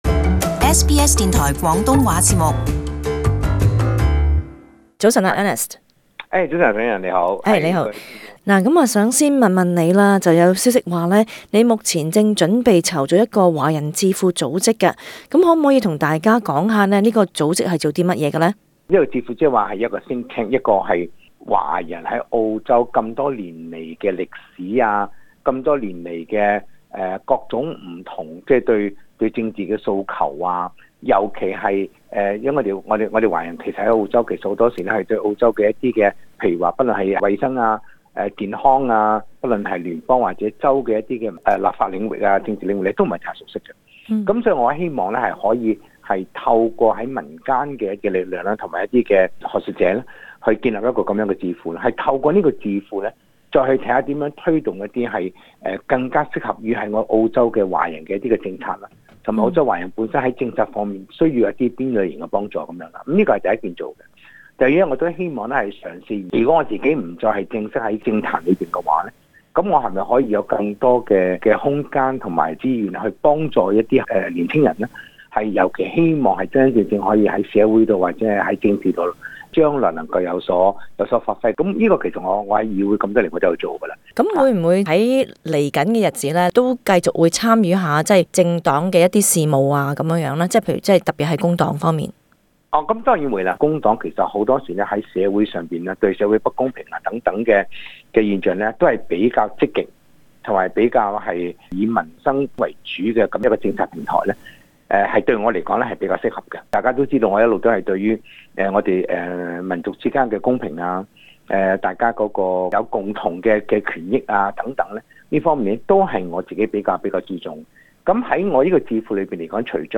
【社區專訪】澳洲對中國存在戒心阻礙華人參政？